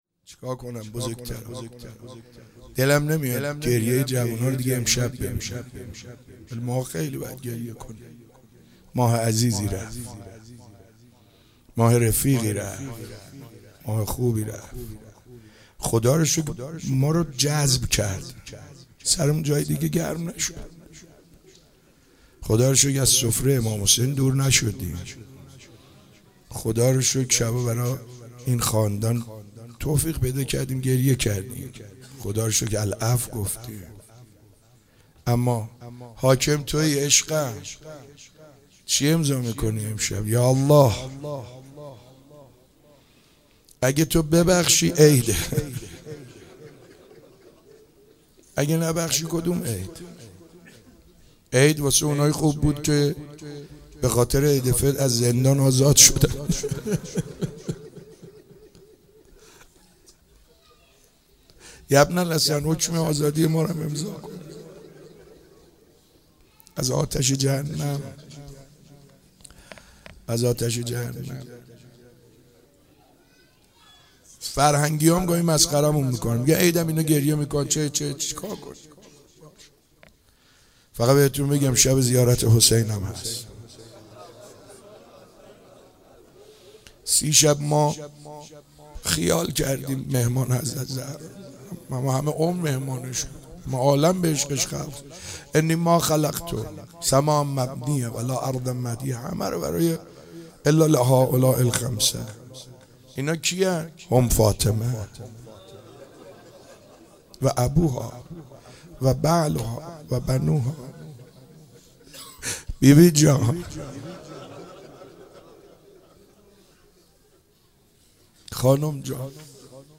عید فطر 96 - روضه